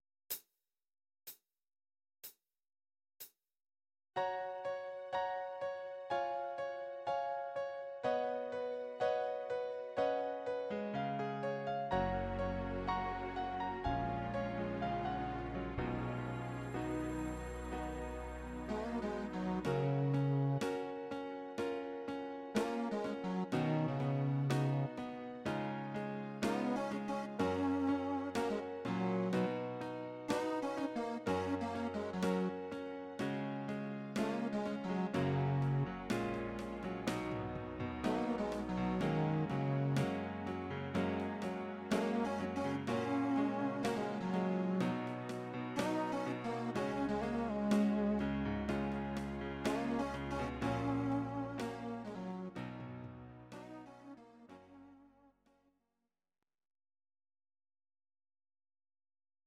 Audio Recordings based on Midi-files
Pop, Duets, 2000s